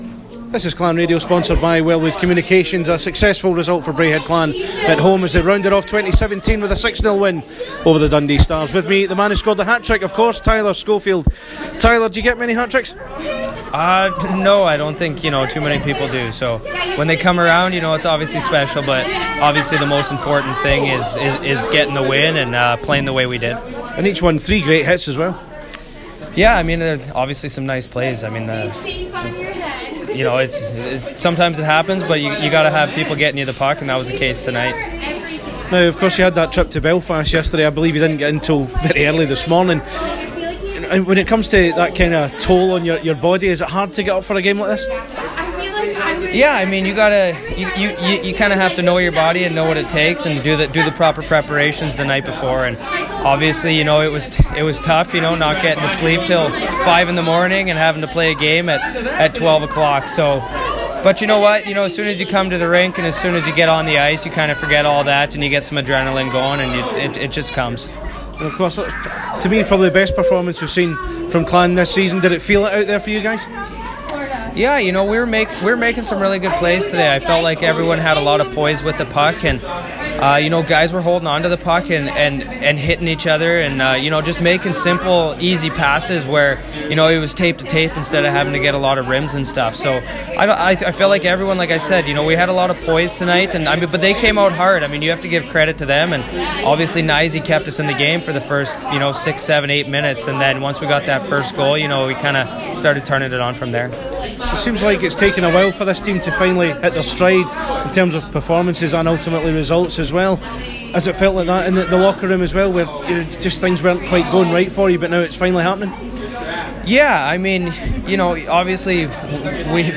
Here, he speaks to Clan Radio about the game